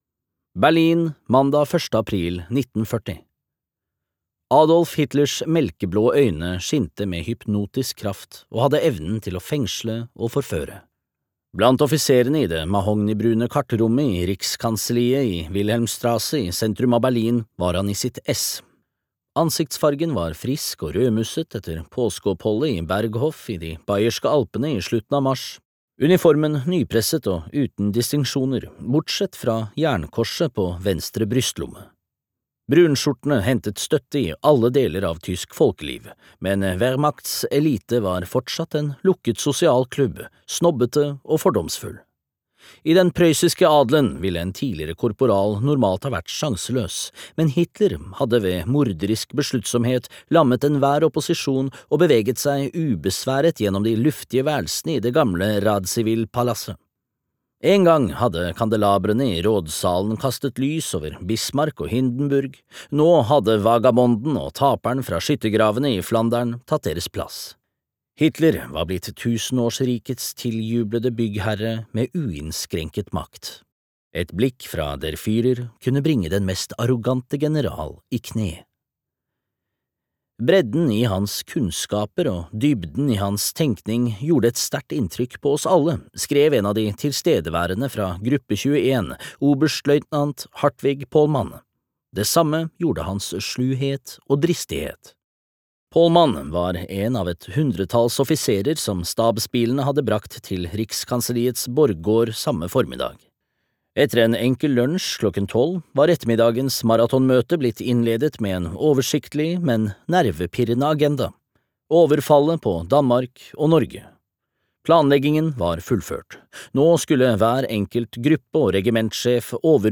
Prosessen - sviket mot Blüchers banemann (lydbok) av Alf R. Jacobsen